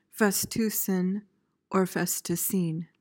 PRONUNCIATION:
(FES-tyuh-syn/seen)